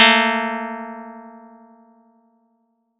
Audacity_pluck_1_14.wav